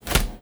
R - Foley 150.wav